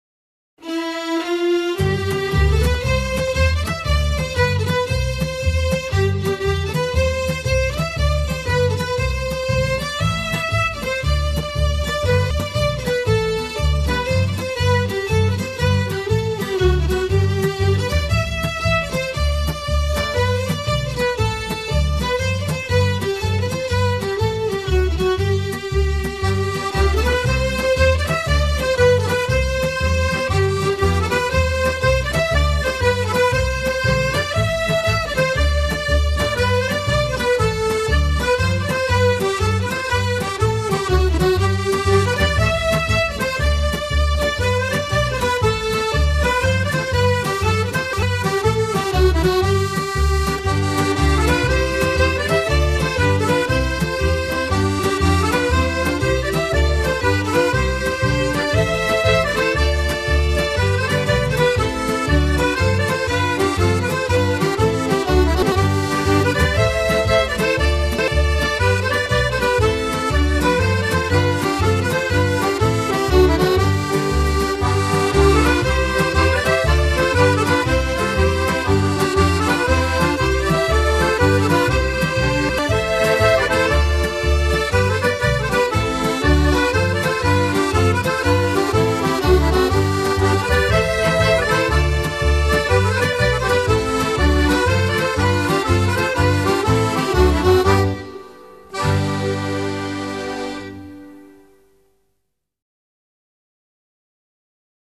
奥尔夫音乐——中提琴和小提琴
中提琴和小提琴.mp3